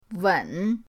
wen3.mp3